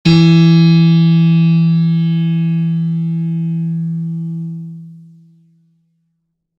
piano-sounds-dev
e2.mp3